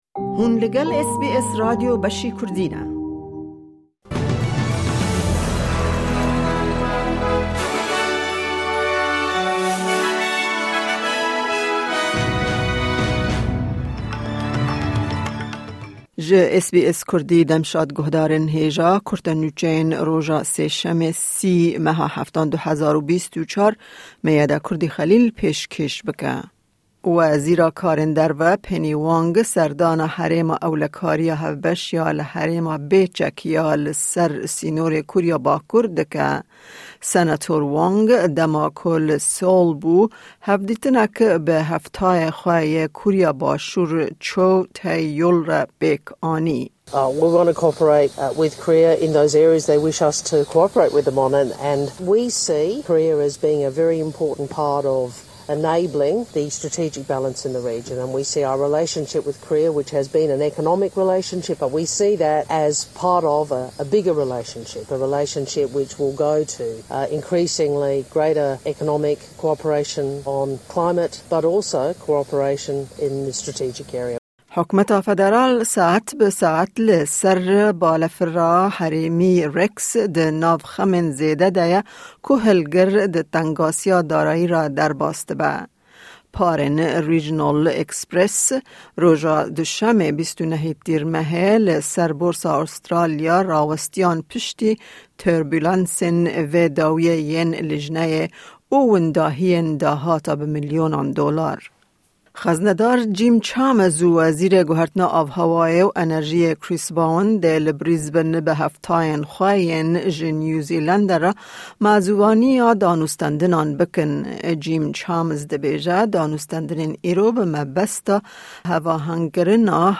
Kurte Nûçeyên roja Sêşemê 30î Tîrmeha 2024